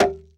Arrow Into Wood 02.wav